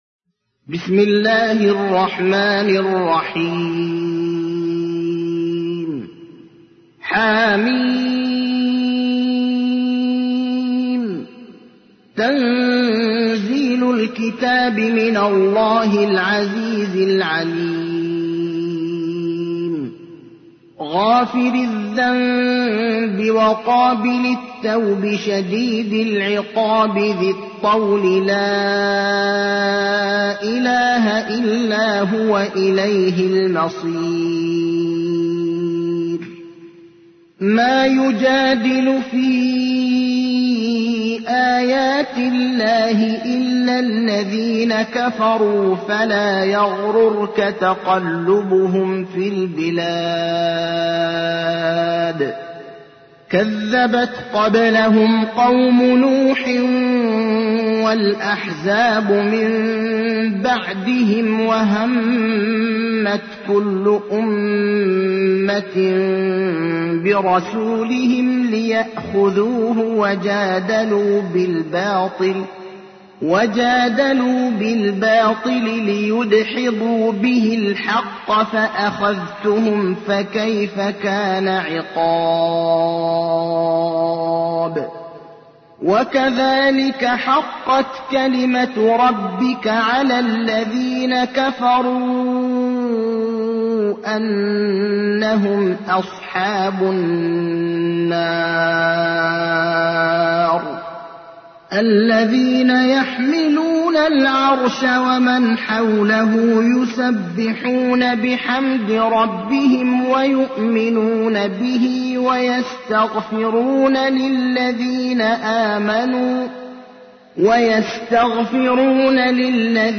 تحميل : 40. سورة غافر / القارئ ابراهيم الأخضر / القرآن الكريم / موقع يا حسين